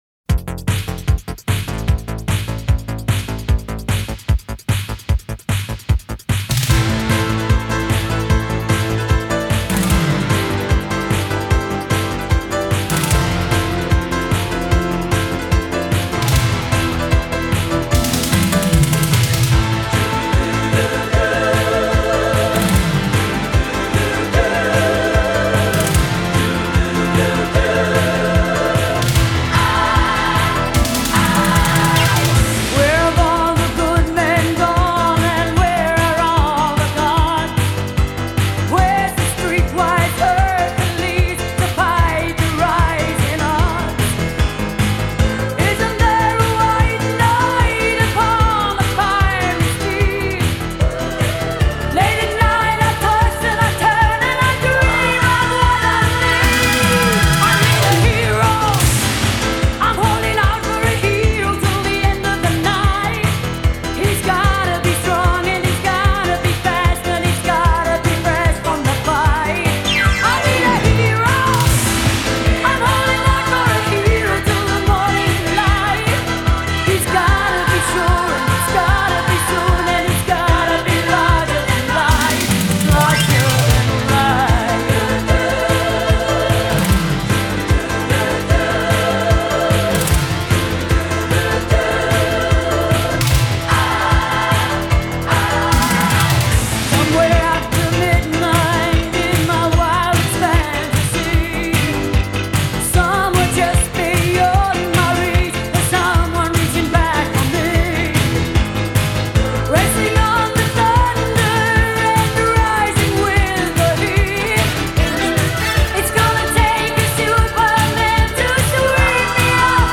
после чего её голос приобрел лёгкую хрипотцу.